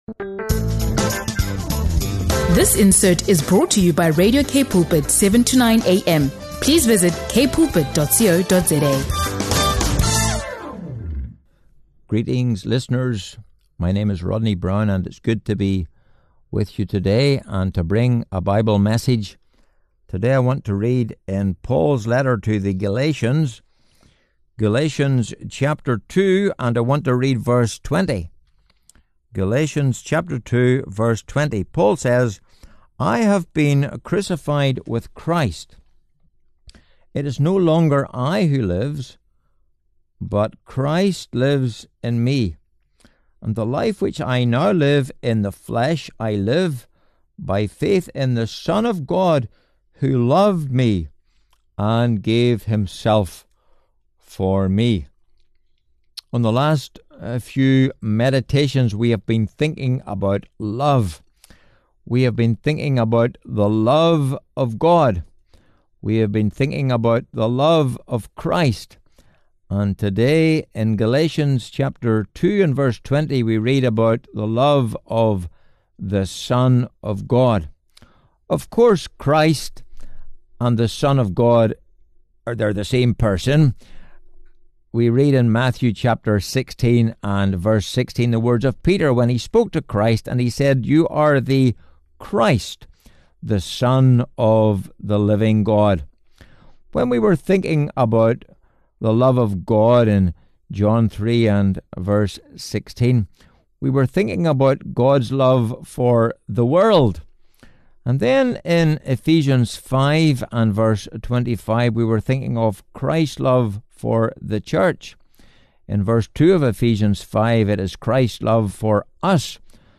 SERMONS & PREKE